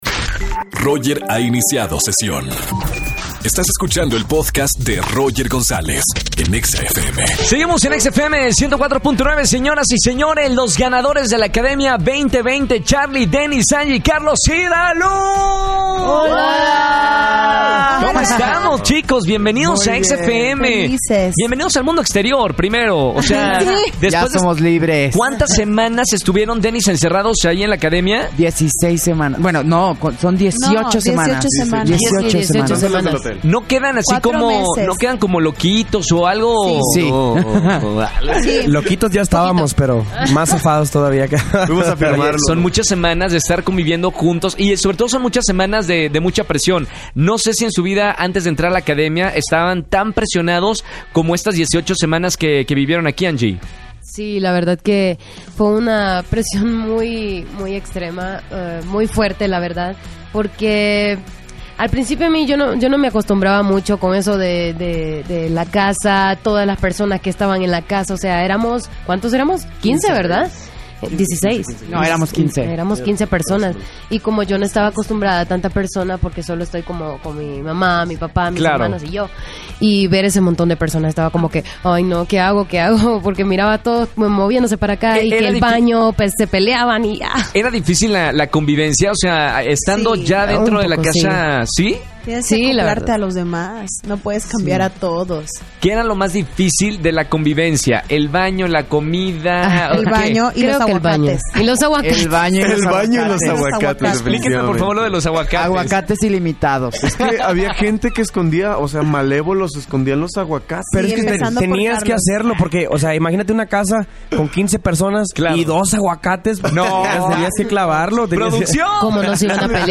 Entrevista con los ganadores de la Academia